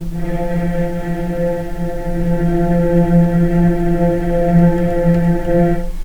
healing-soundscapes/Sound Banks/HSS_OP_Pack/Strings/cello/ord/vc-F3-pp.AIF at f6aadab7241c7d7839cda3a5e6764c47edbe7bf2
vc-F3-pp.AIF